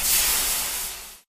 fizz.ogg